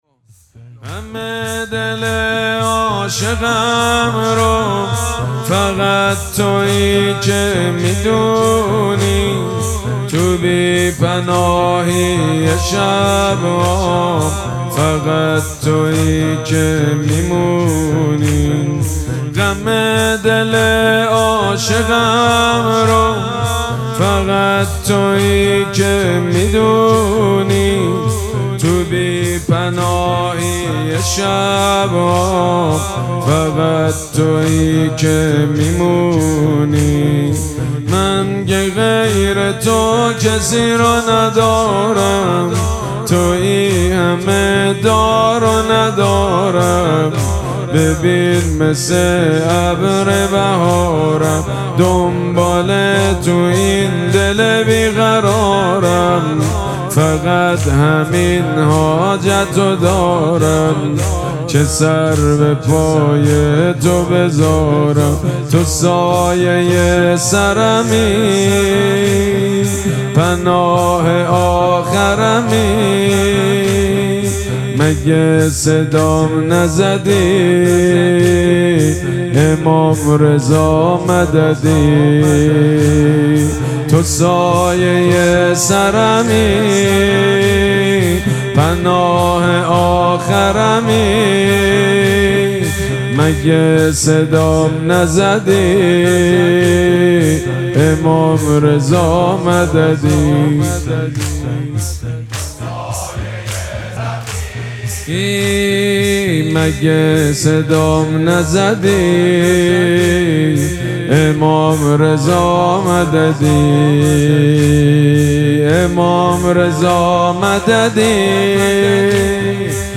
مراسم مناجات شب هشتم ماه مبارک رمضان
حسینیه ریحانه الحسین سلام الله علیها
مداح
حاج سید مجید بنی فاطمه